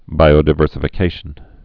(bīō-dĭ-vûrsə-fĭ-kāshən, -dī-)